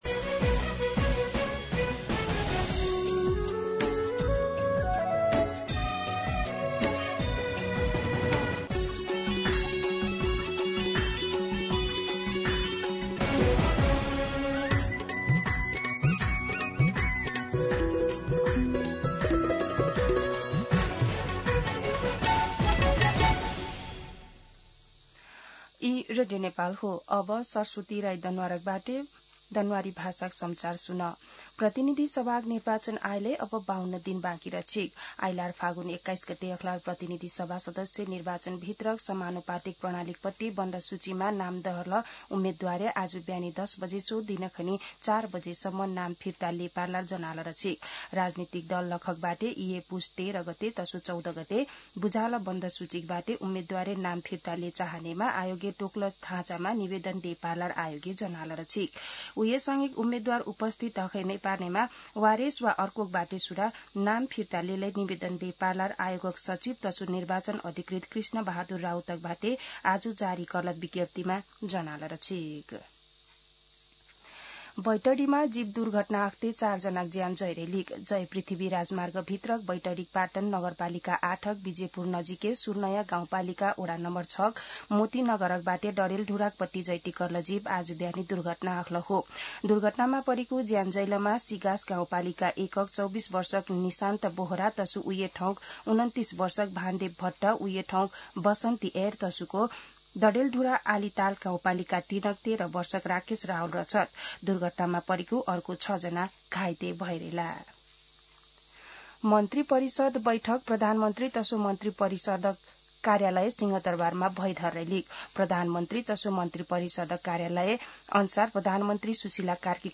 दनुवार भाषामा समाचार : २८ पुष , २०८२
Danuwar-News-9-28.mp3